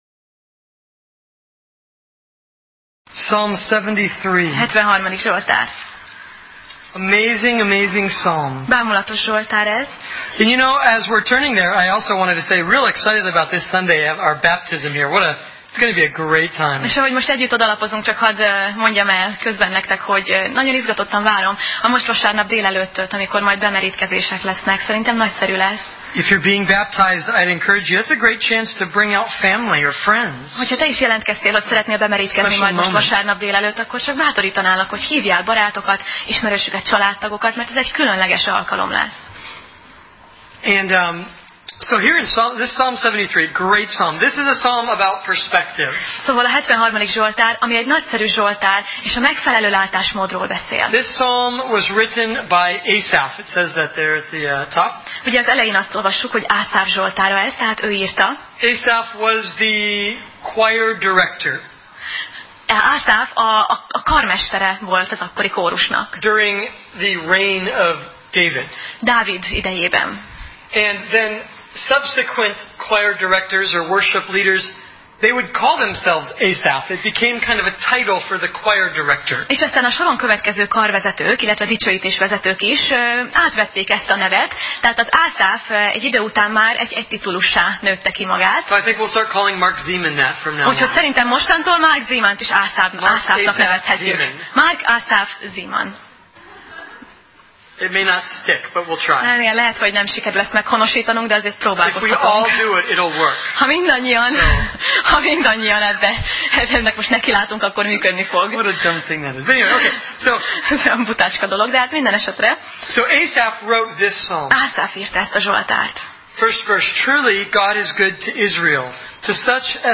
Sorozat: Zsoltárok Passage: Zsoltárok (Psalm) 73 Alkalom: Szerda Este